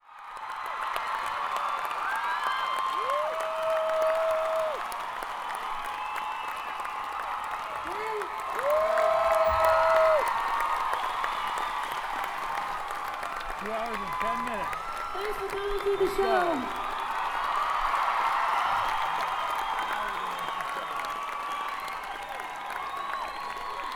lifeblood: bootlegs: 1995-04-21: sports and rec center - saratoga springs, new york